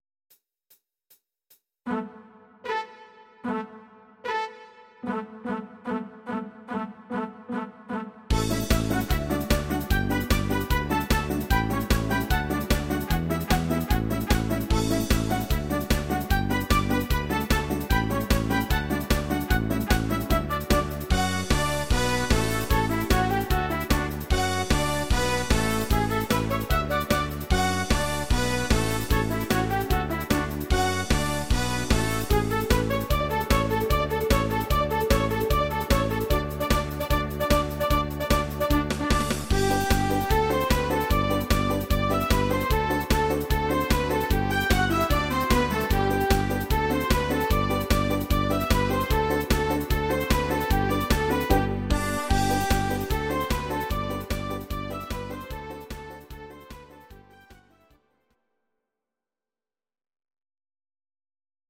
These are MP3 versions of our MIDI file catalogue.
Please note: no vocals and no karaoke included.
Your-Mix: Classical (264)